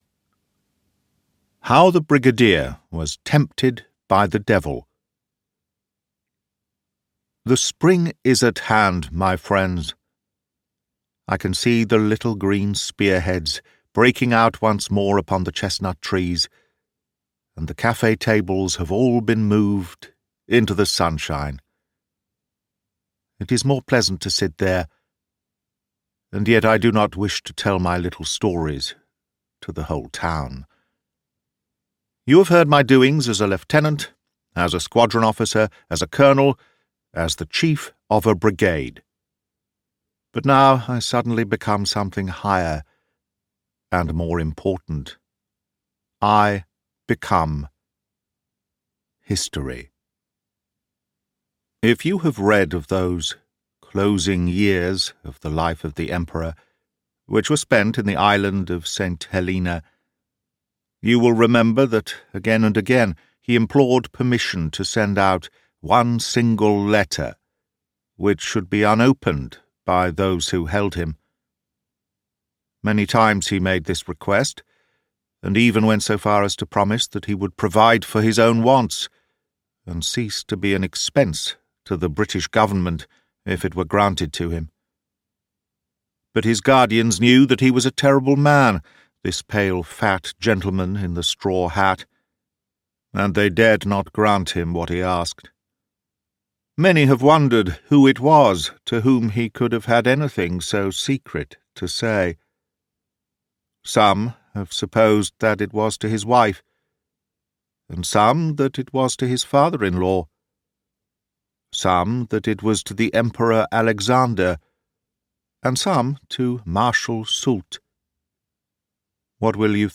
Recollections of Captain Wilkie by Arthur Conan Doyle - Full Audiobook